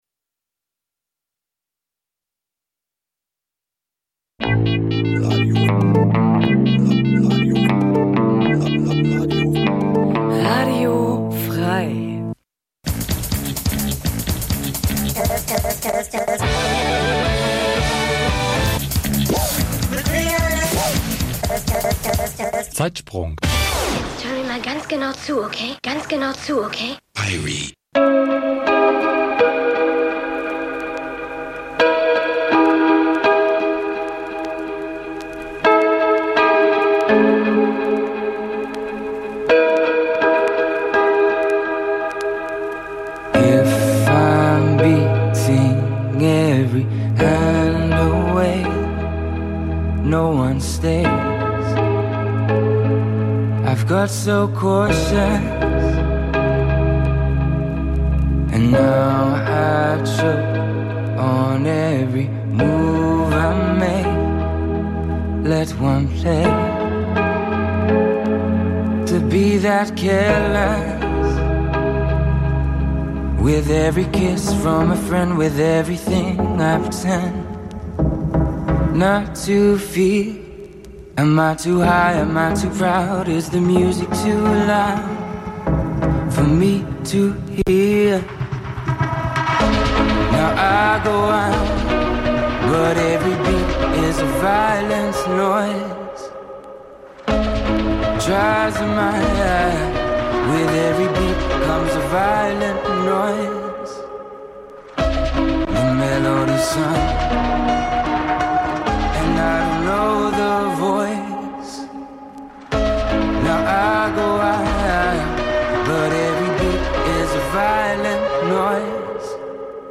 Schr�ge Originale stehen noch schr�geren Coverversionen gegen�ber.
Musik vergangener Tage Dein Browser kann kein HTML5-Audio.